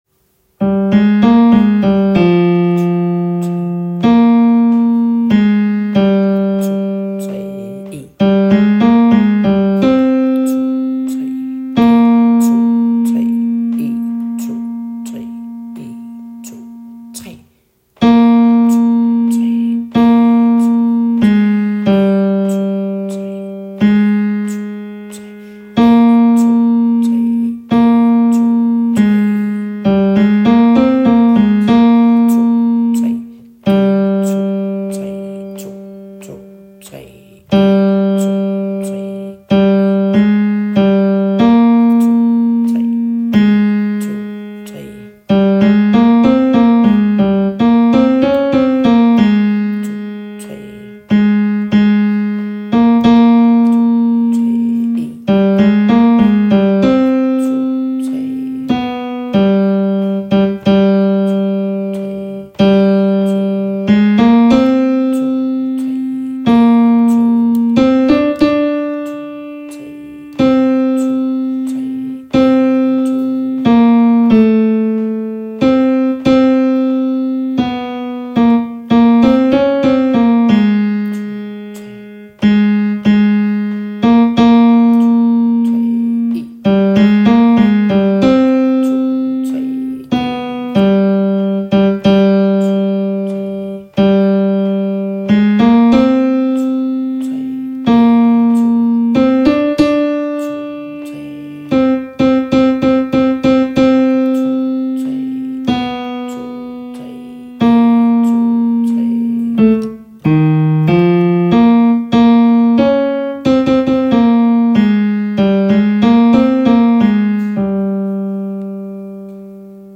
Kun koret